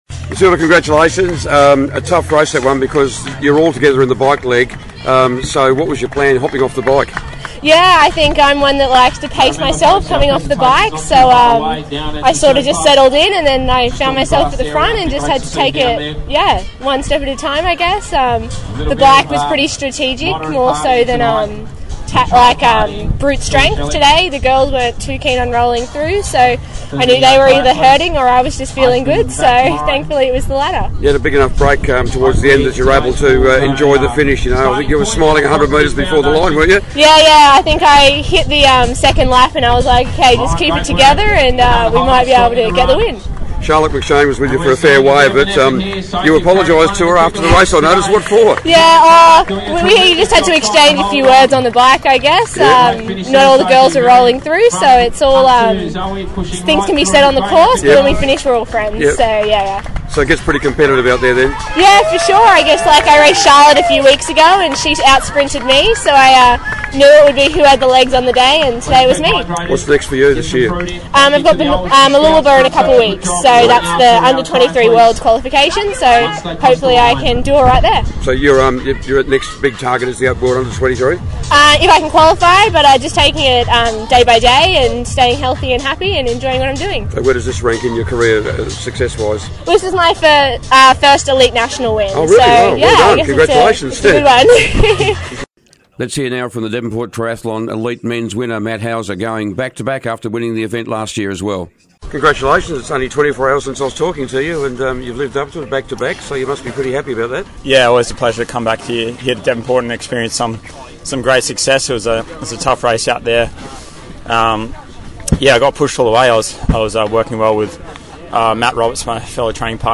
winners of the Devonport Triathlon shortly after the finish .